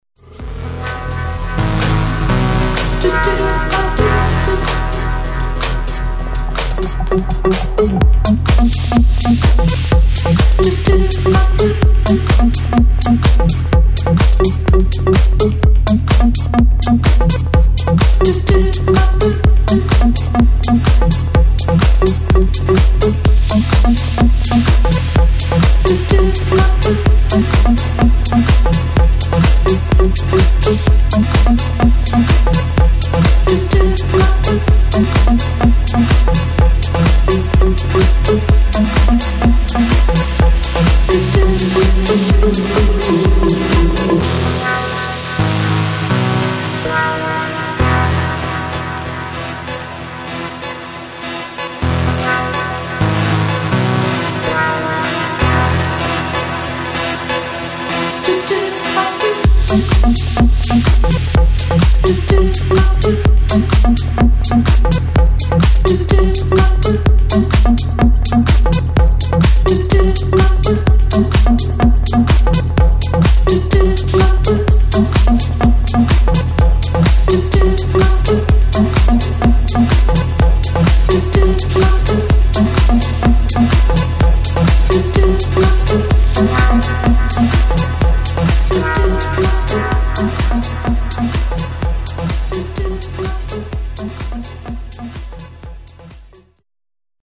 [ TECHNO | HOUSE ]